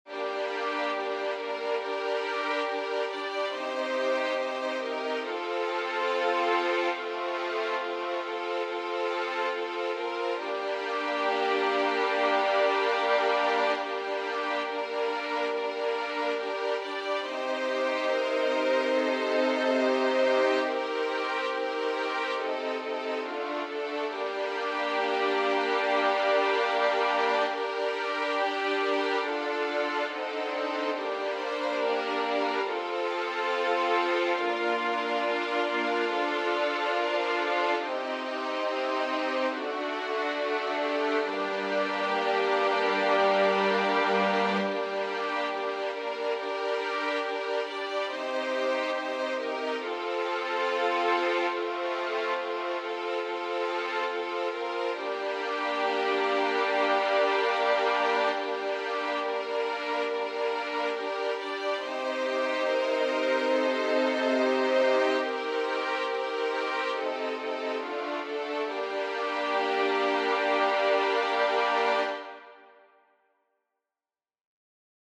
• Catégorie : Chants de Méditation